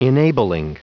Prononciation du mot enabling en anglais (fichier audio)
Prononciation du mot : enabling